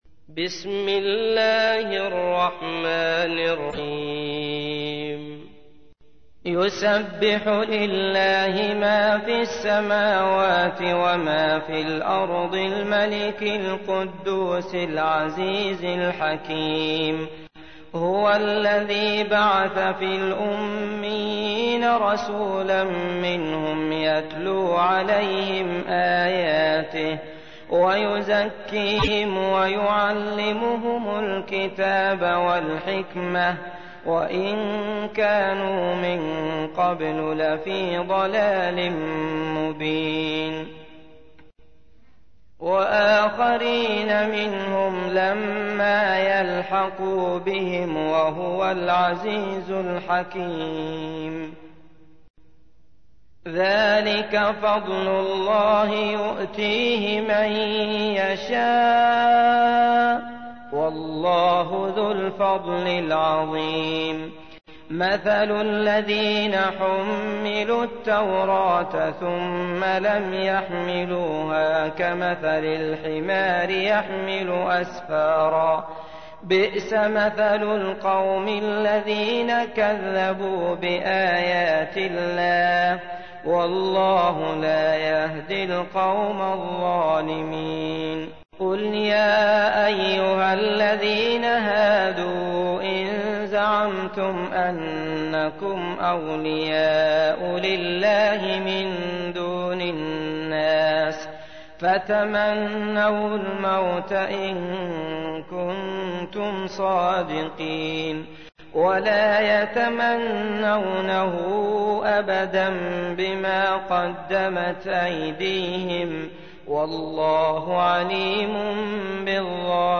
تحميل : 62. سورة الجمعة / القارئ عبد الله المطرود / القرآن الكريم / موقع يا حسين